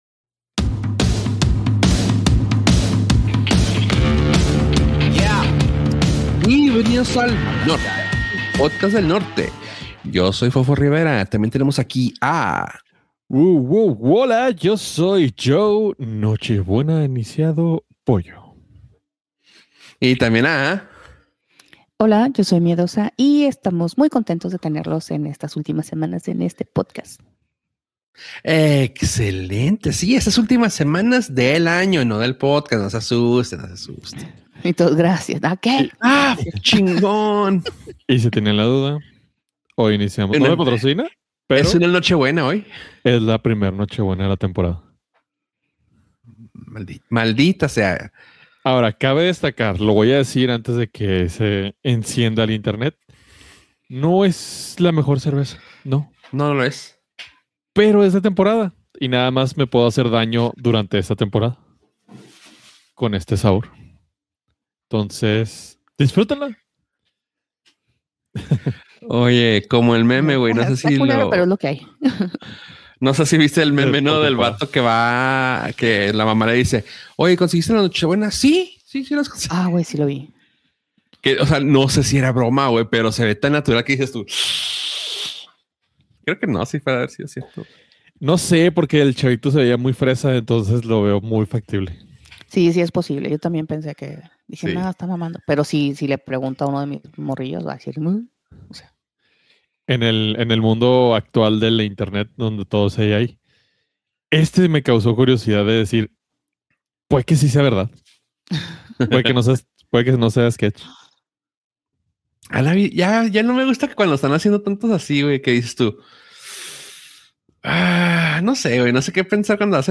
Nortcast Nortcast Un podcast de entretenimiento, tecnología y cultura pop. Presentado desde el Norte (Ciudad Juárez, Chihuahua).